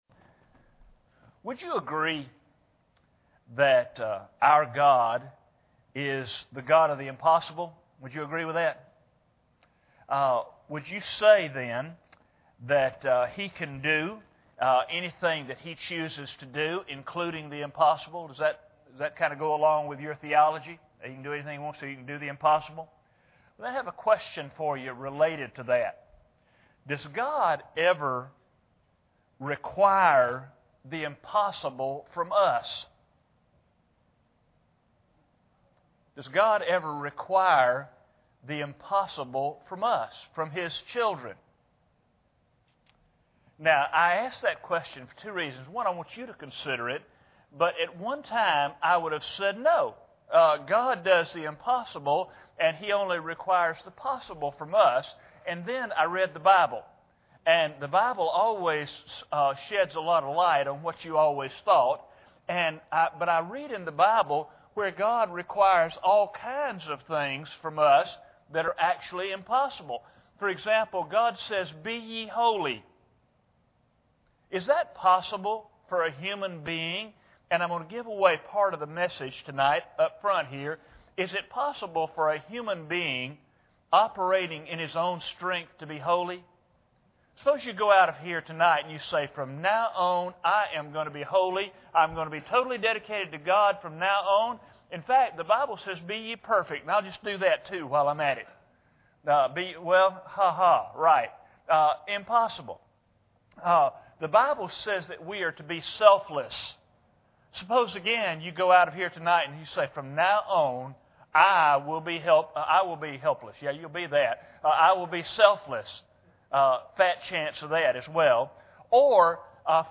Passage: Romans 12:14-19 Service Type: Sunday Evening